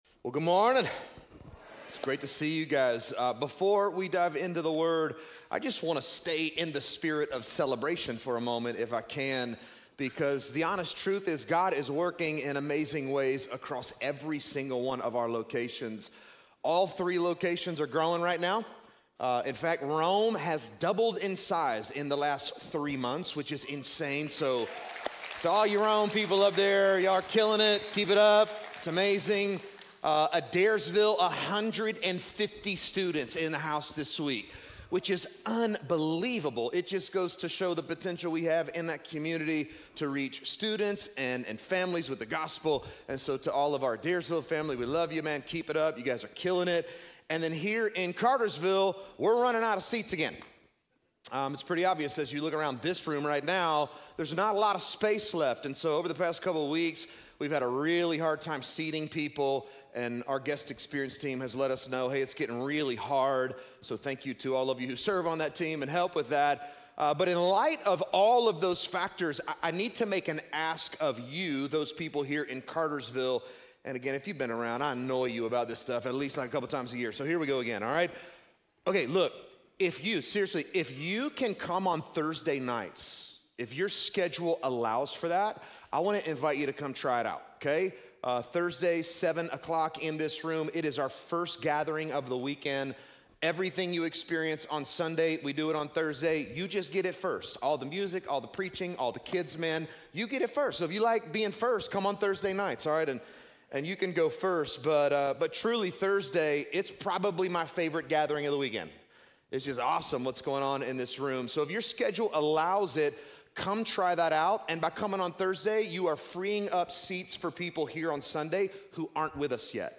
This message is from our "The Gospel of John" series: "The True Light."